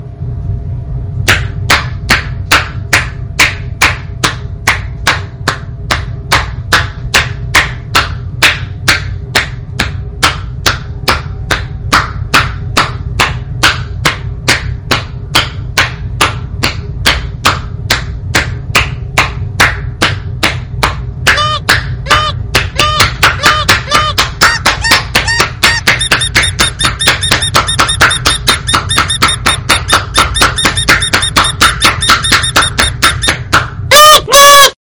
assclap11.mp3